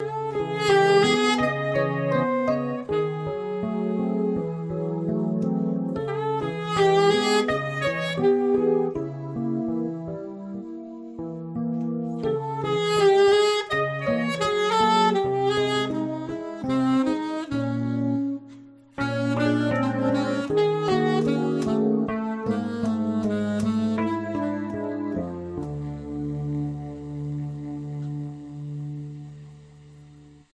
ein Hamburger Filmmusikprojekt